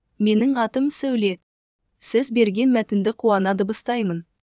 Синтез речи
Сауле говорит на казахском доброжелательно
Сауле может говорить добродушно и строго, на казахском и на русском, поэтому умеет быть разной и подстраиваться под сценарии клиента.